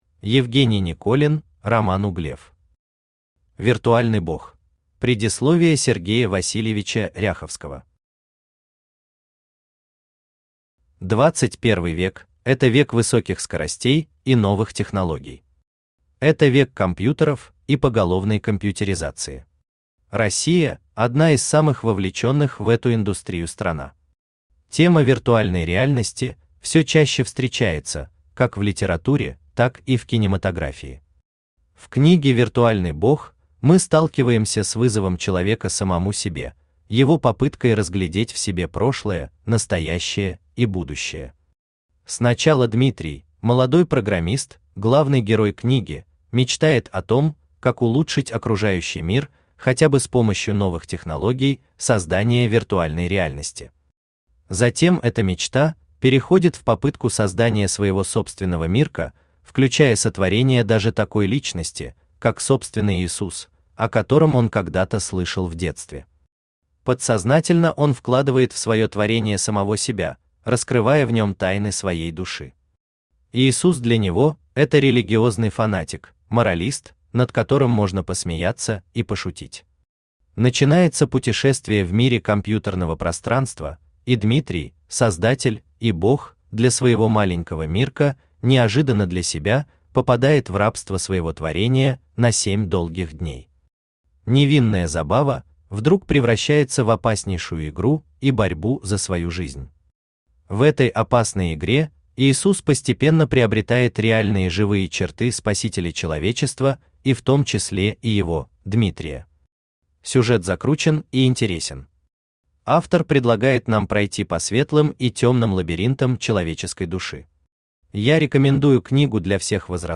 Аудиокнига Виртуальный Бог | Библиотека аудиокниг
Aудиокнига Виртуальный Бог Автор Роман Романович Углев Читает аудиокнигу Авточтец ЛитРес.